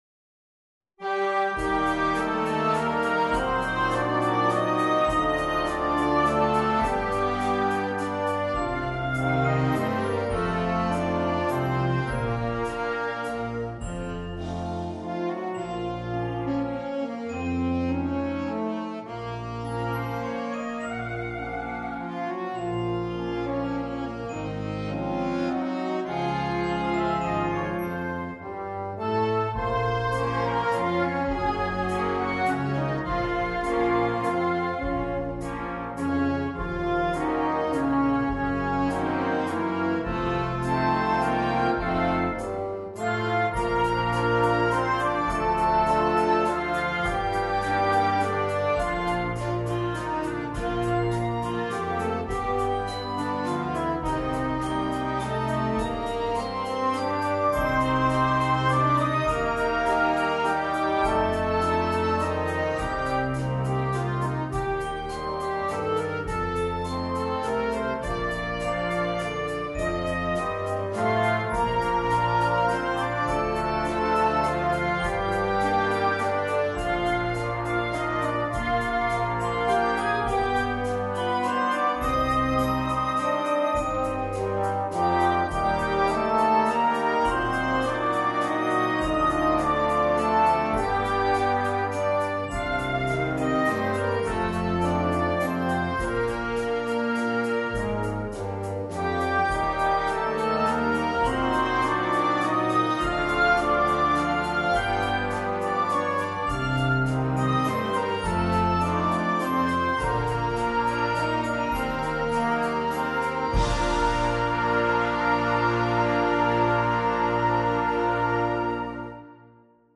Una pagina dolce di musica per un concerto.
MUSICA PER BANDA